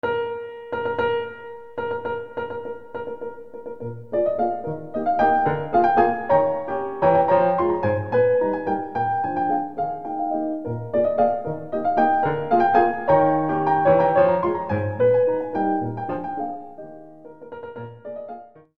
performed on an acoustic piano